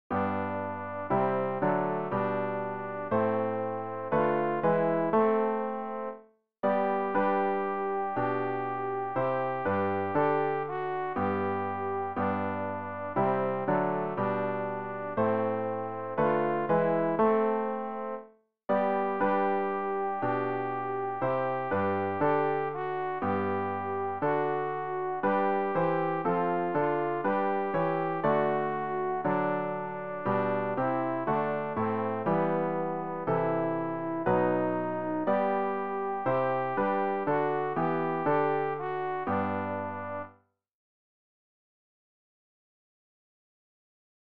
rg-794-ich-glaube-alt.mp3